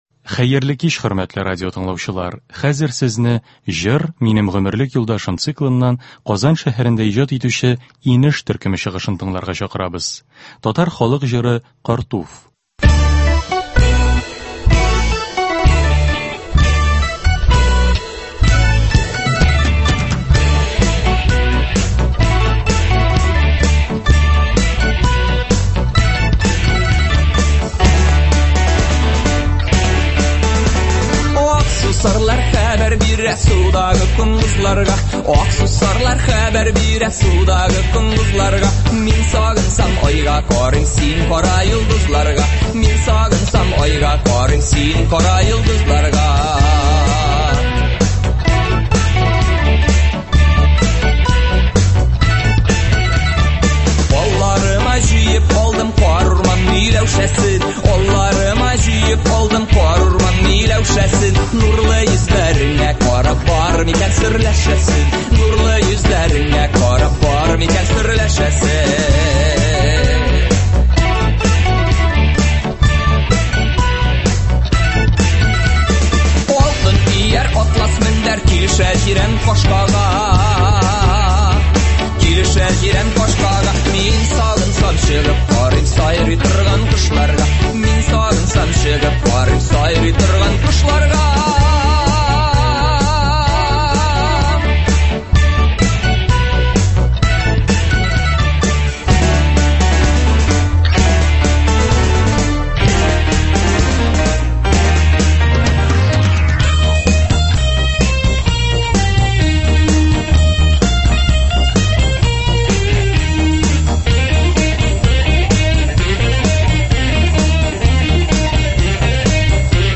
Әдәби-музыкаль композиция.